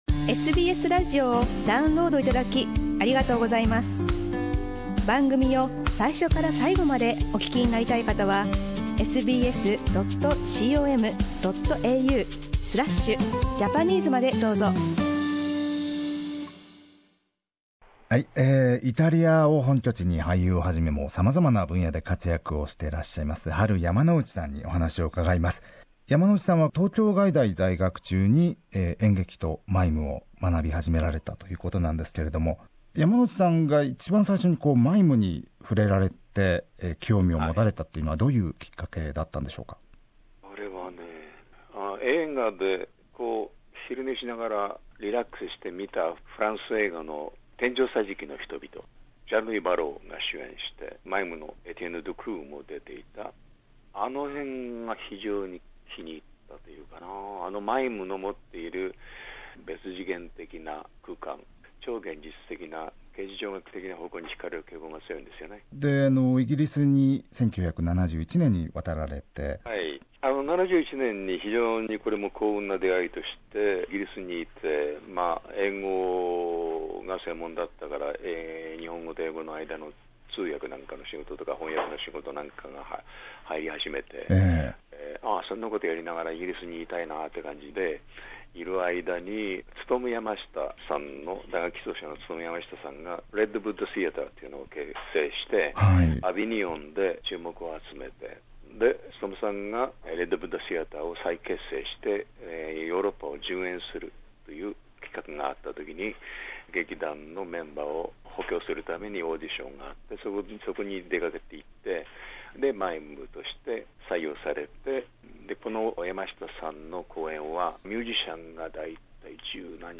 シドニーで撮影が行われたハリウッド映画、『ウルヴァリン・サムライ』にも出演するなど、イタリアを本拠地にしながら世界的に活躍する日本人俳優のハル・ヤマノウチさん。映画や舞台、翻訳など幅広く活躍されているヤマノウチさんに、今年42年目となるイタリア生活が始まったきっかけなどについて、お話を伺いました。